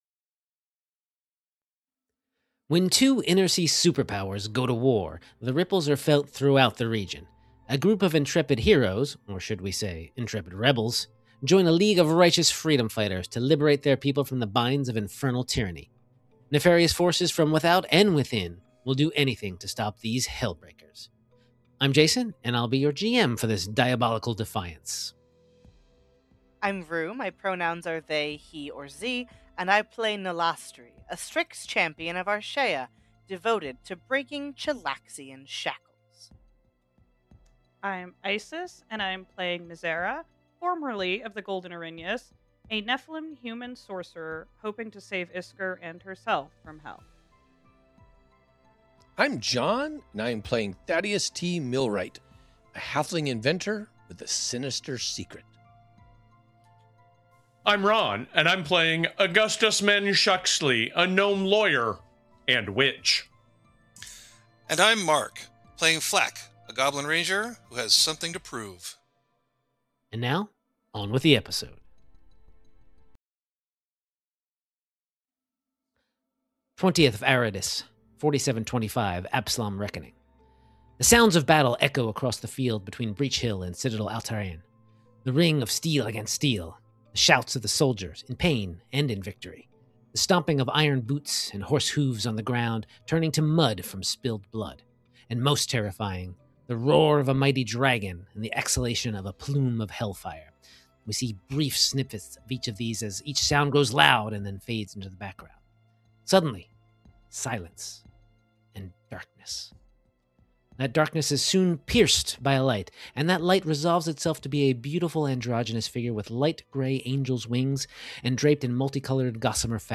Actual Play Podcast